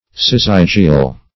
Syzygial \Sy*zyg"i*al\, a. Pertaining to a syzygy.